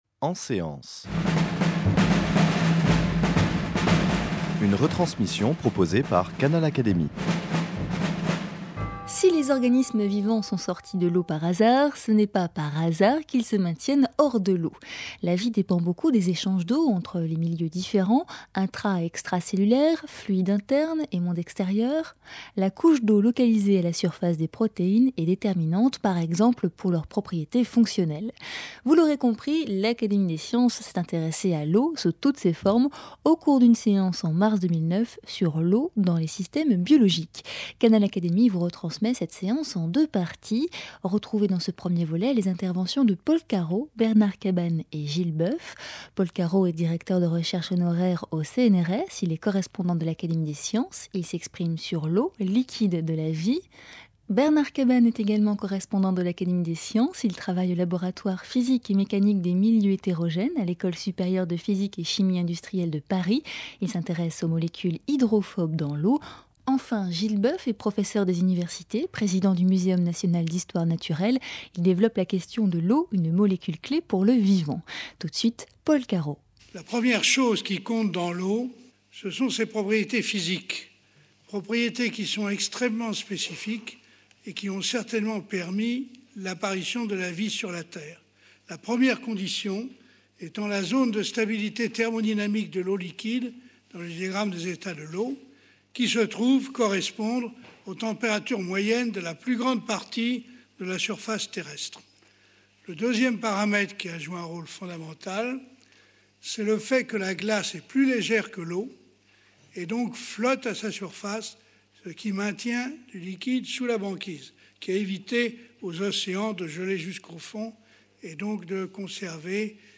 L’Académie des sciences a consacré une séance à l’eau dans les systèmes biologiques en mars 2009. Retransmission vous en est faite en deux parties sur Canal Académie.